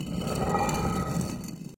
stone_door.ogg